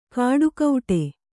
♪ kāḍu kauṭe